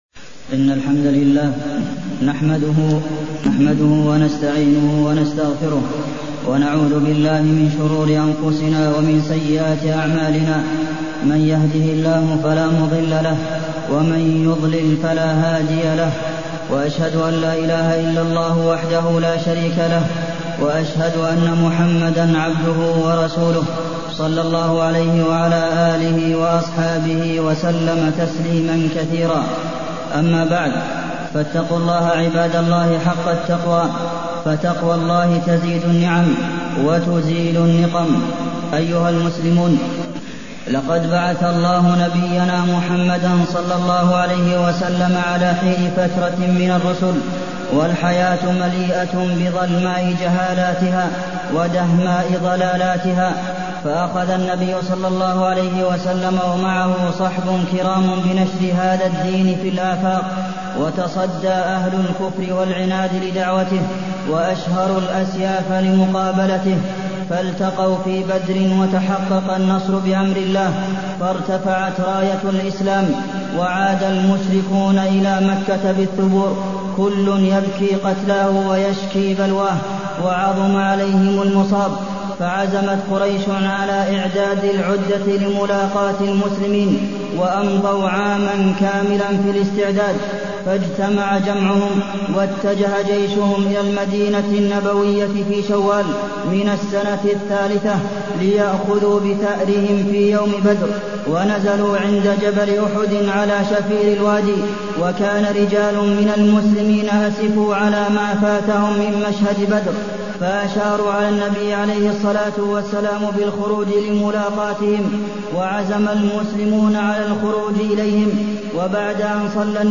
تاريخ النشر ١ ربيع الثاني ١٤٢٢ هـ المكان: المسجد النبوي الشيخ: فضيلة الشيخ د. عبدالمحسن بن محمد القاسم فضيلة الشيخ د. عبدالمحسن بن محمد القاسم غزوة أحد وما فيها من العبر